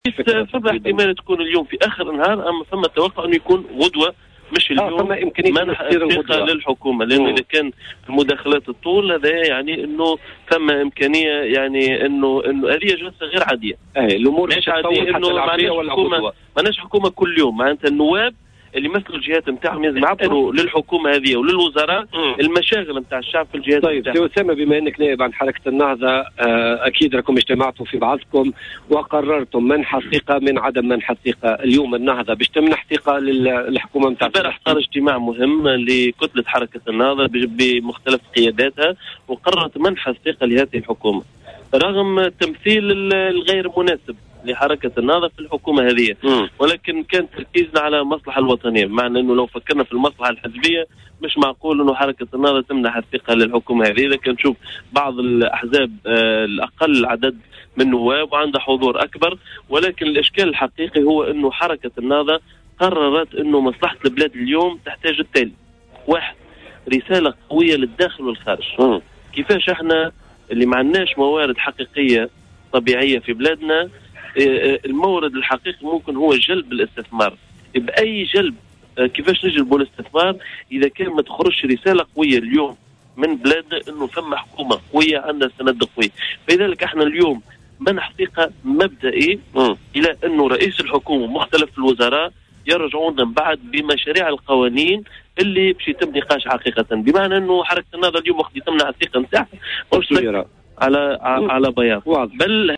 Invité de l'émission Politica du mercredi 4 février 2015, Oussema Sghaier que son parti a décidé d'accorder sa confiance au gouvernement composé par Habib Esssid, bien que le parti est sa représentativité inadéquate au sein de cabinet à celle qu’il détient à l’Assemblée.